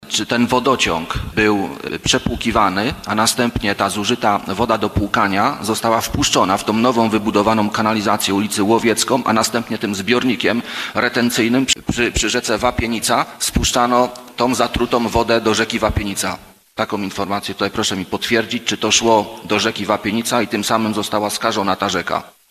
Kwestia styrenu i technologii remontu magistrali oraz mnóstwo innych wątków związanych ze skażeniem wody dostarczanej do części mieszkań w Bielsku-Białej – pojawiło się w trakcie dzisiejszej nadzwyczajnej sesji miejskiej.